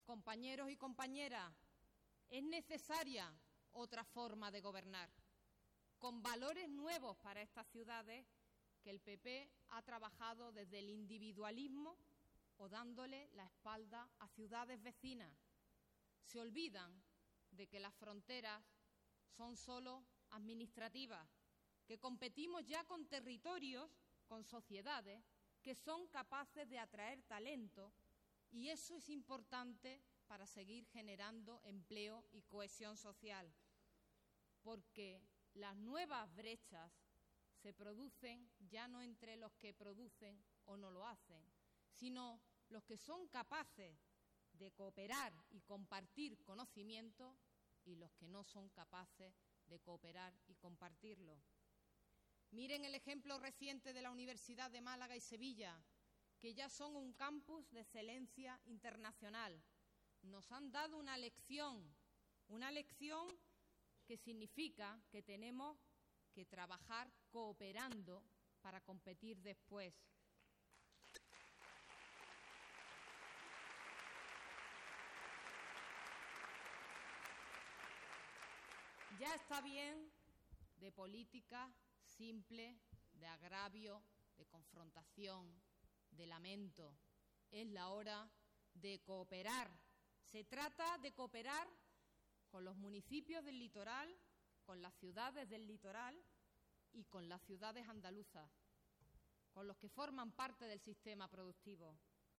La candidata socialista a la Alcaldía de Málaga, María Gámez, ha apostado hoy, en el marco del Foro Litoral del PSOE de Málaga, por el abandono de las políticas del agravio y la confrontación a favor de la cooperación entre ciudades.